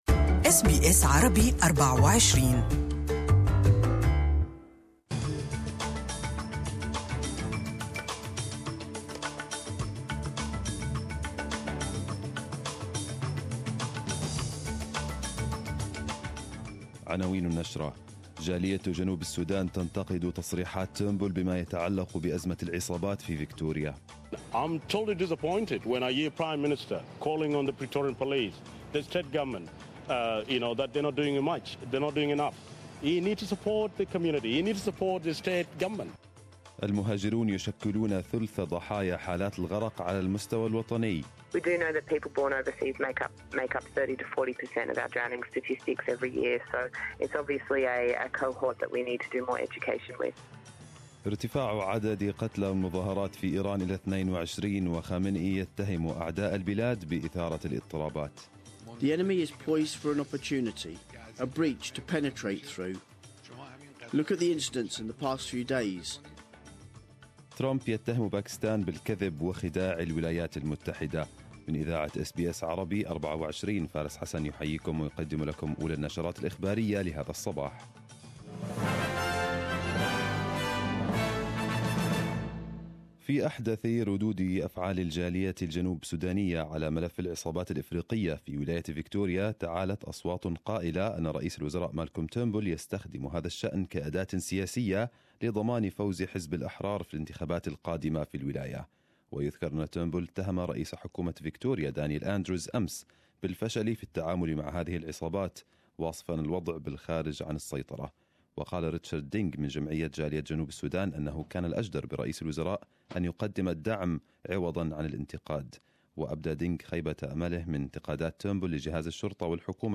Arabic news Bulletin 03/01/2018